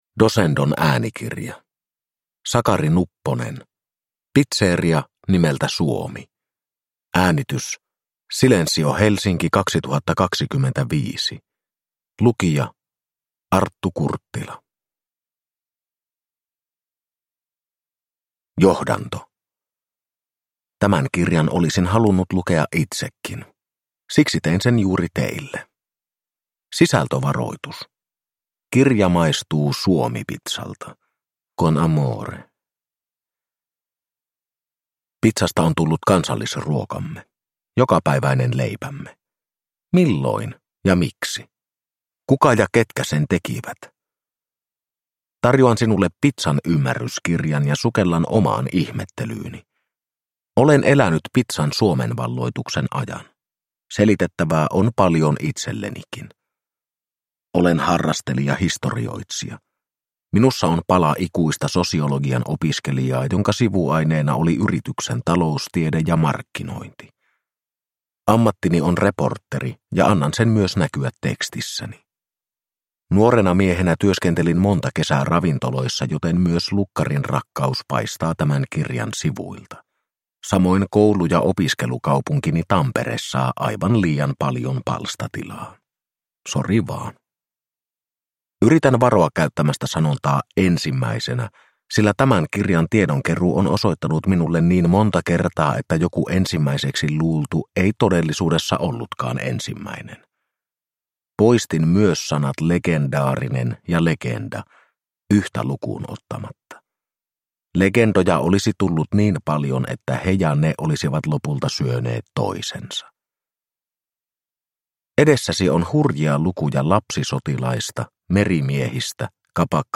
Pizzeria nimeltä Suomi – Ljudbok